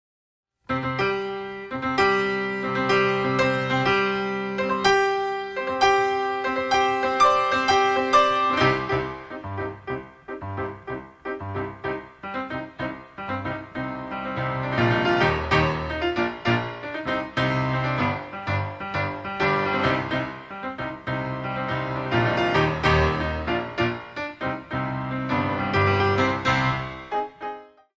The Original PIANO Compositions
good for ballet and modern lyrical